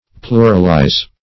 Pluralize \Plu"ral*ize\, v. t. [imp. & p. p. Pluralized; p.